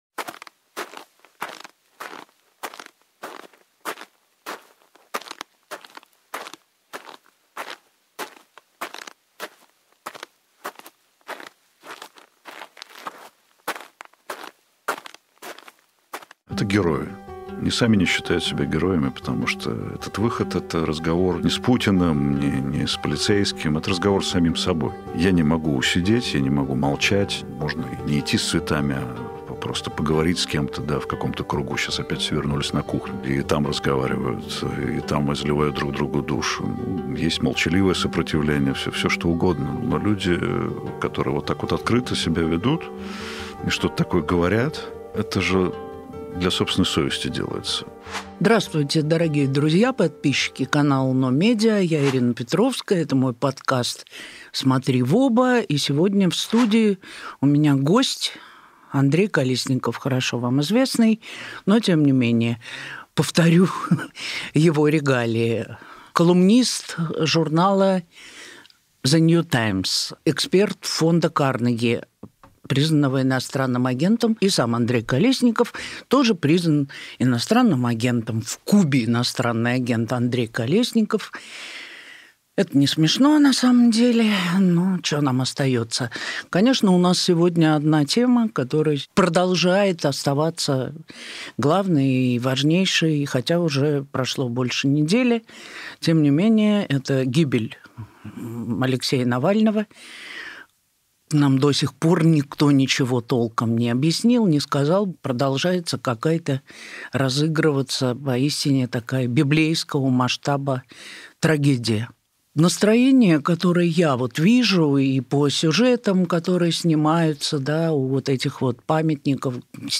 Эфир Ирины Петровской